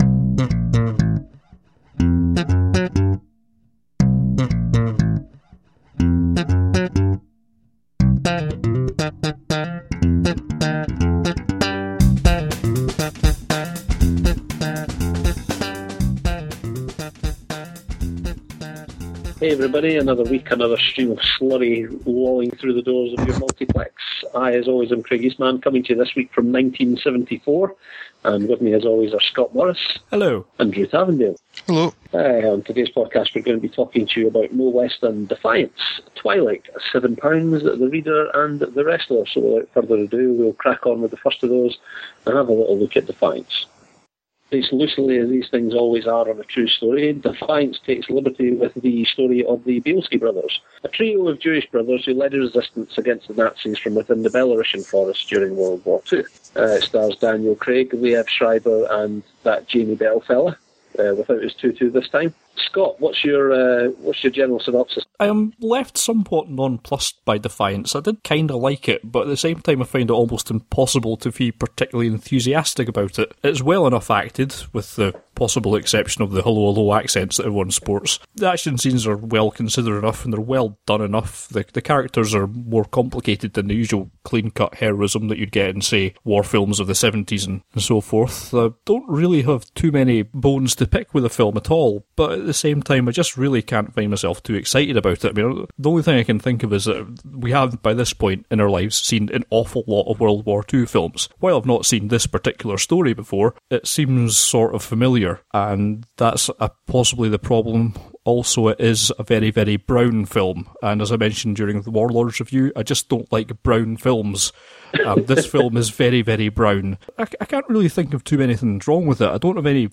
it's been cleaned up as best we can but it's below our usual standard.